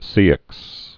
(sēĭks)